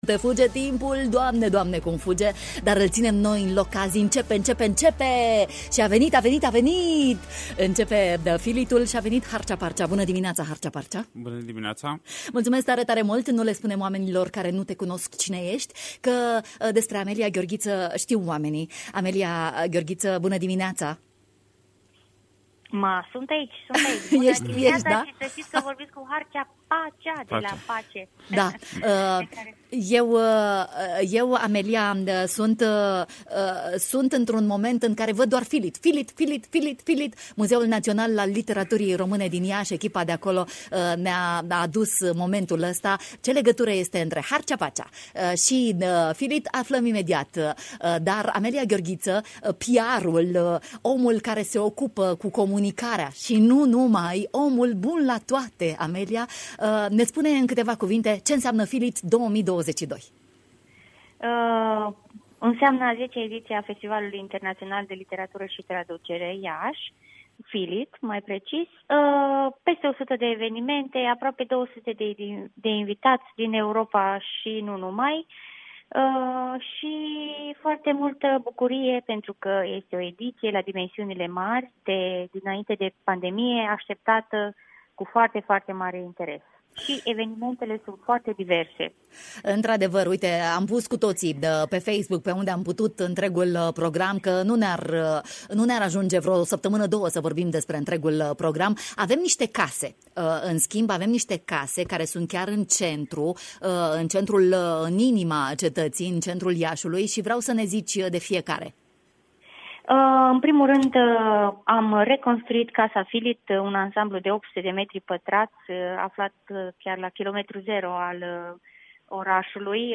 în direct la marinalul de la Radio Iași: